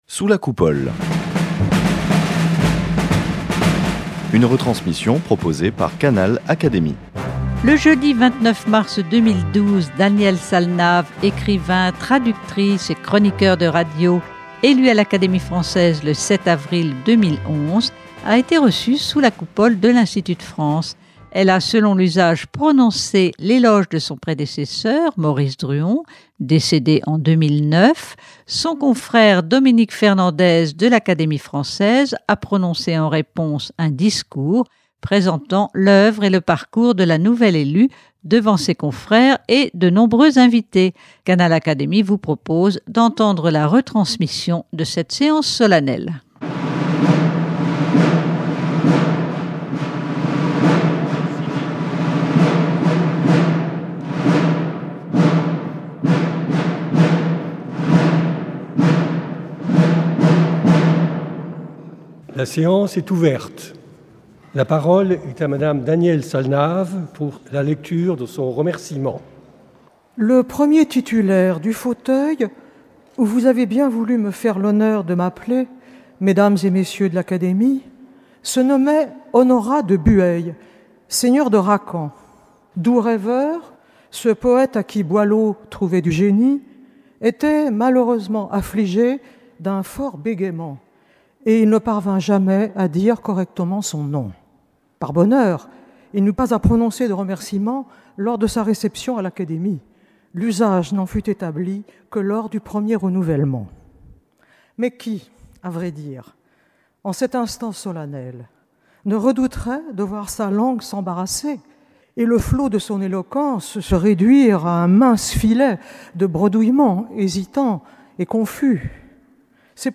Elle a, selon l’usage prononcé l’éloge de son prédécesseur, Maurice Druon (décédé en 2009). Son confrère Dominique Fernandez, de l’Académie française a prononcé en réponse, un discours présentant l’œuvre et le parcours de la nouvelle élue, devant ses confrères et de nombreux invités. Canal Académie vous propose d’entendre la retransmission de cette séance solennelle.